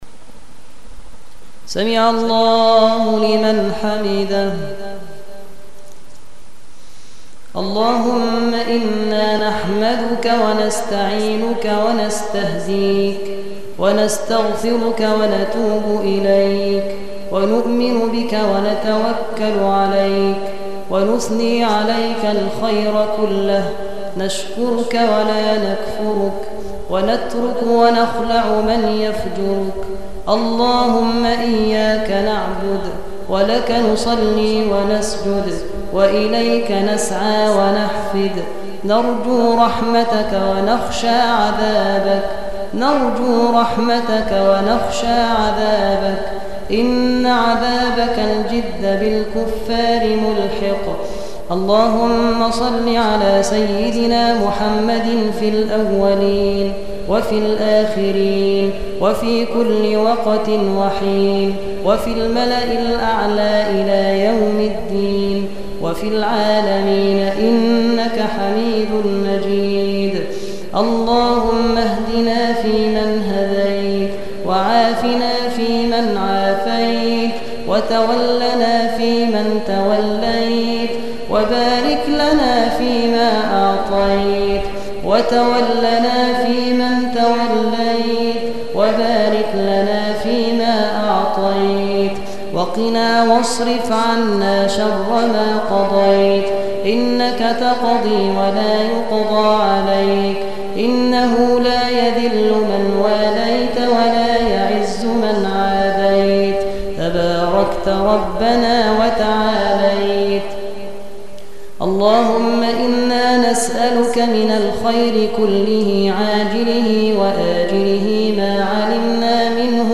دعاء القنوت
أدعية وأذكار